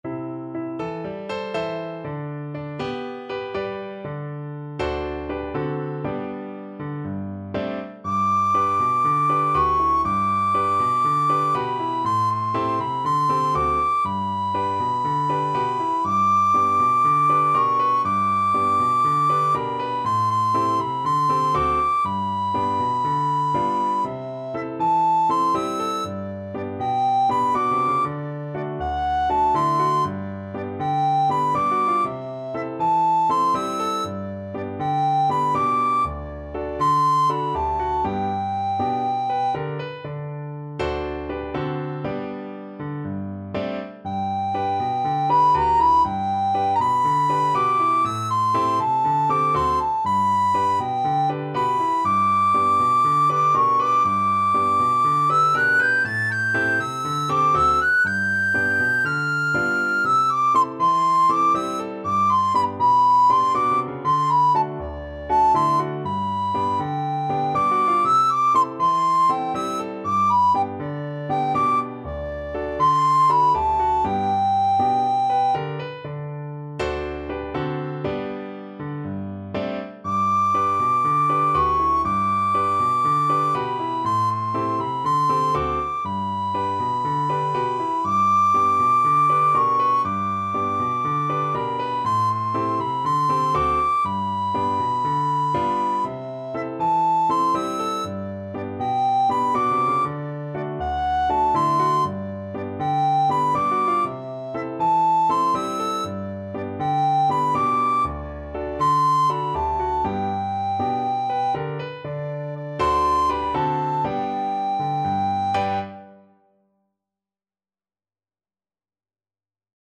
Free Sheet music for Soprano (Descant) Recorder
Recorder
Calypso = 120
E5-A6
G major (Sounding Pitch) (View more G major Music for Recorder )
4/4 (View more 4/4 Music)